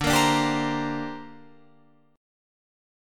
Listen to D7sus4#5 strummed